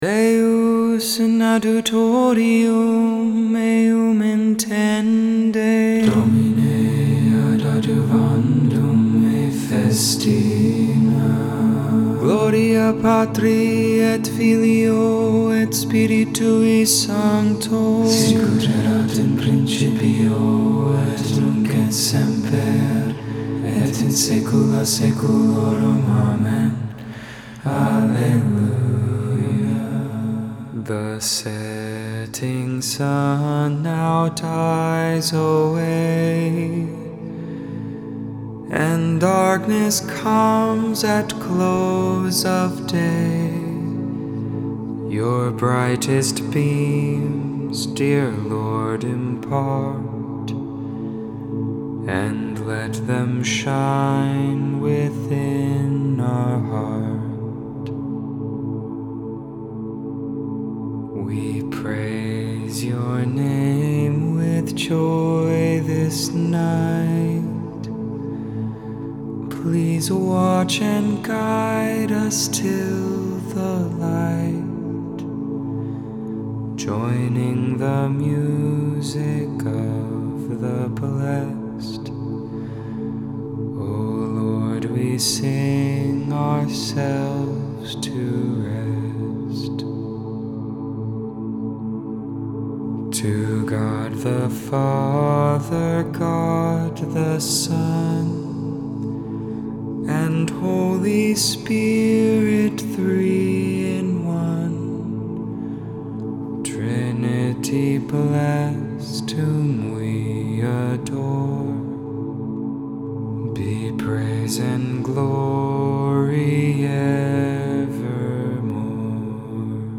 1.12.21 Vespers (Tues Evening Prayer)